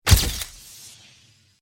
Вспышка фотоаппарата